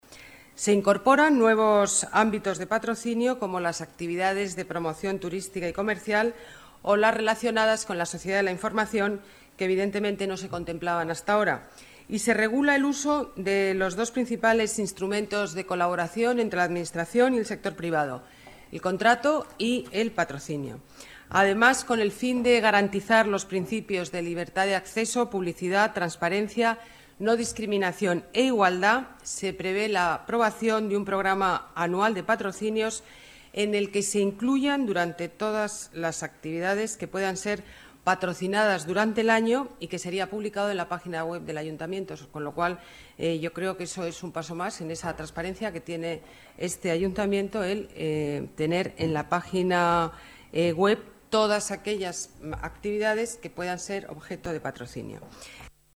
Nueva ventana:Declaraciones de Ana Botella